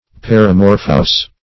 Paramorphous \Par`a*mor"phous\, a. (Min.)